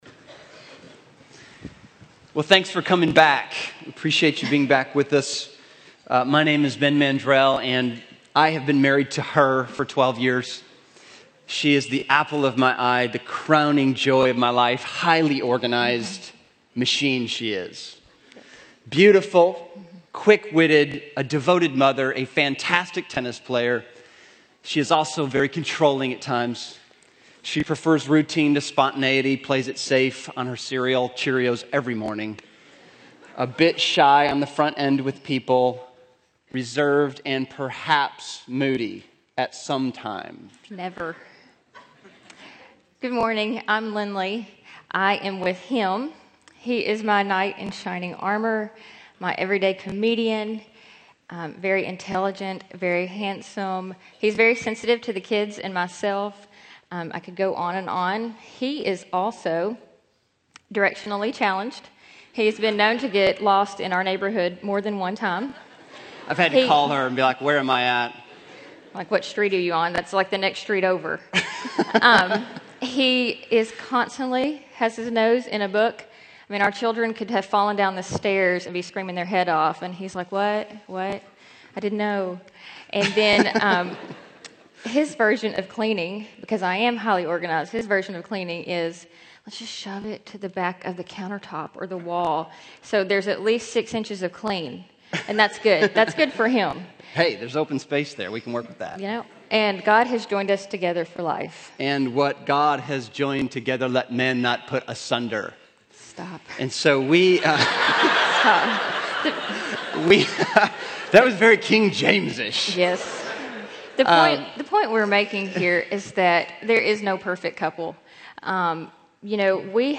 Crabtree Family Life Chapel